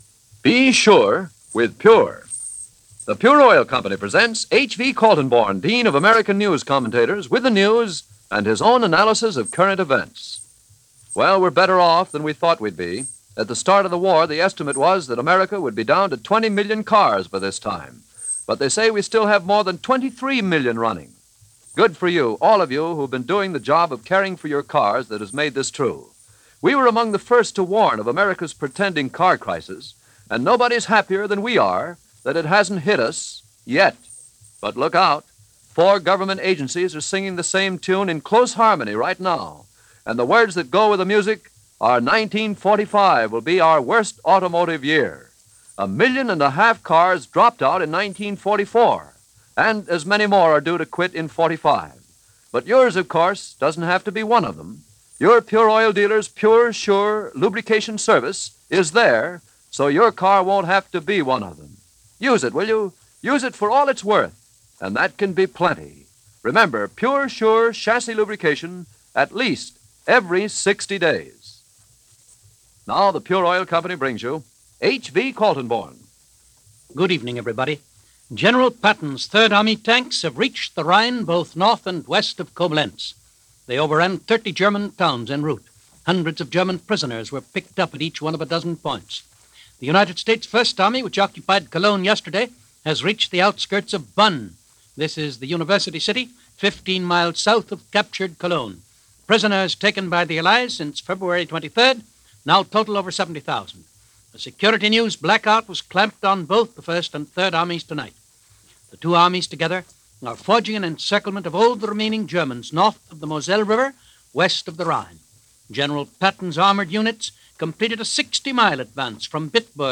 News-March-7-1945.mp3